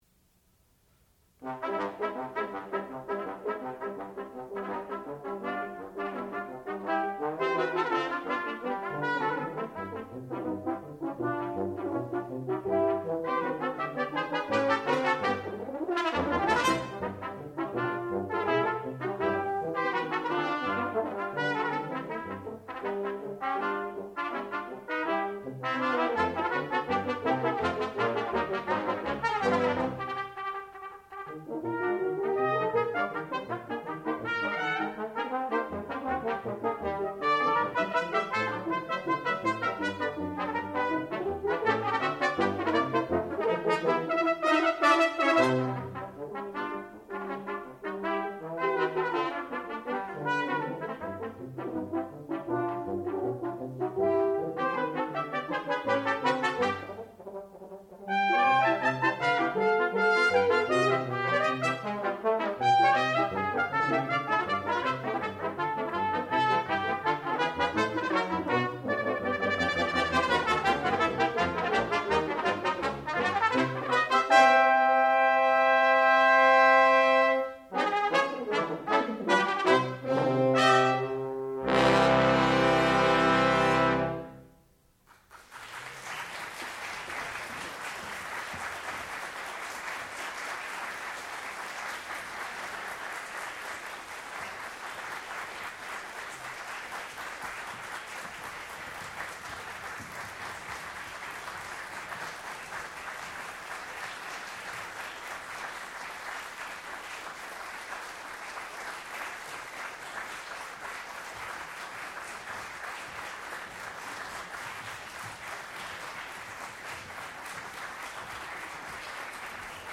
sound recording-musical
classical music
trumpet
tuba